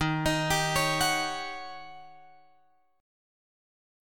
D# 7th Sharp 9th